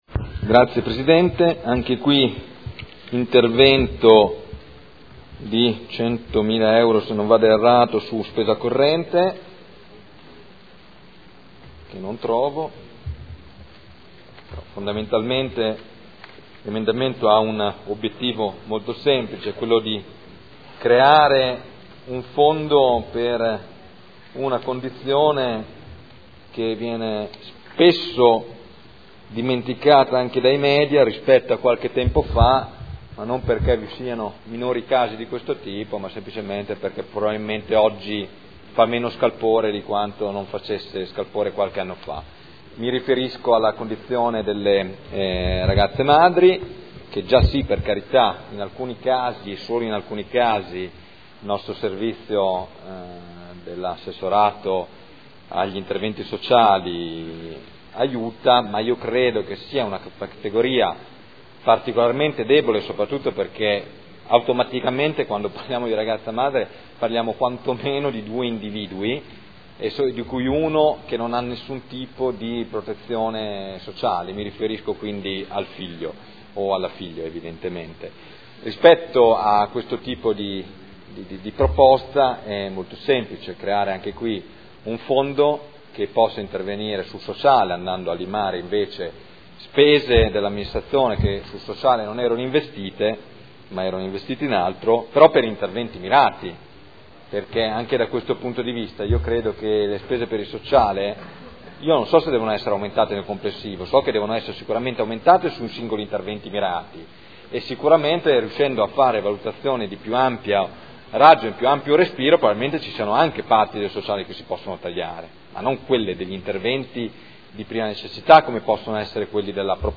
Michele Barcaiuolo — Sito Audio Consiglio Comunale